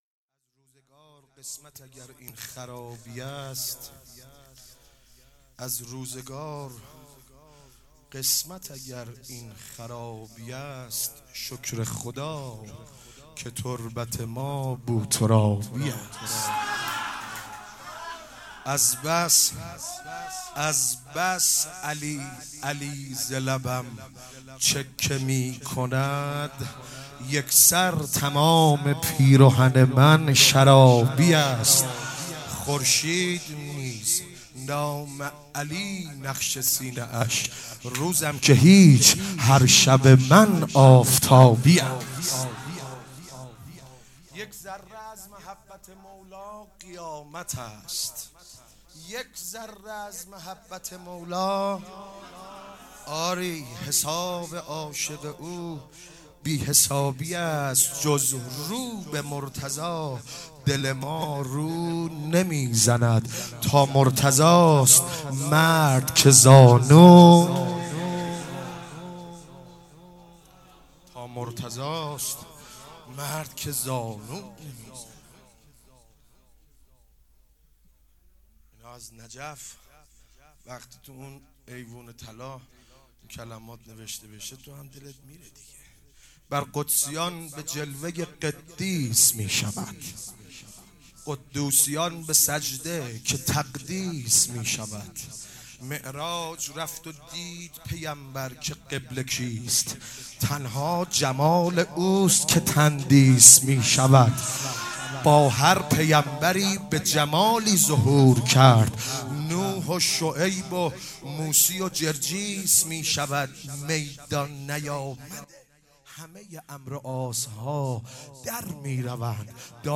ولادت حضرت علی علیه السلام
مدح مولودی